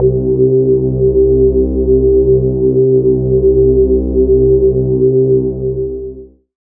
Amb1n2_d_synth_c_bass.wav